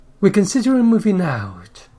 We’re  /wɪəis often reduced reduced to /wi/.